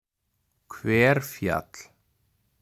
Hverfjall (Icelandic pronunciation: [ˈkʰvɛrˌfjatl̥]
Hverfjall_pronunciation.ogg.mp3